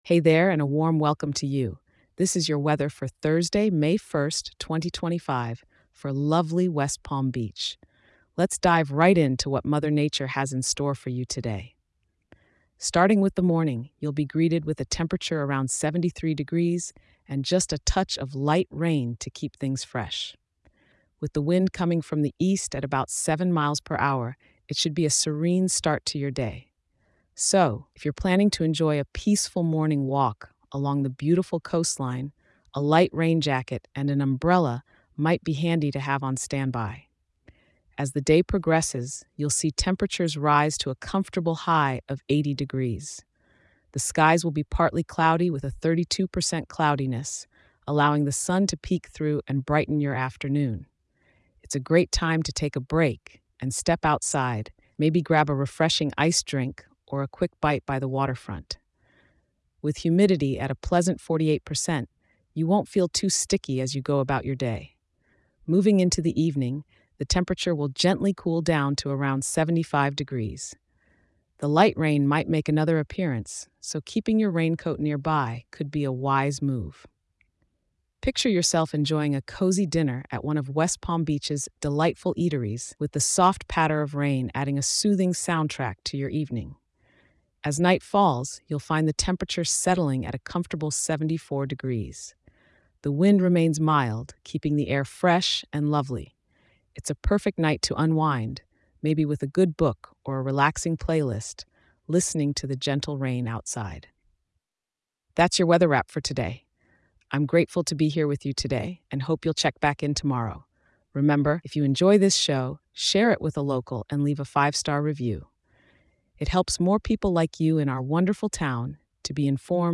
Note: Every element of this Podcast is proudly powered by AI and a fun